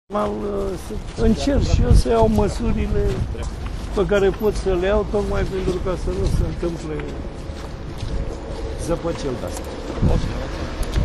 Edilul Șef a mai afirmat că încearcă să ia măsuri pentru ca asemenea lucruri să nu se mai întâmple.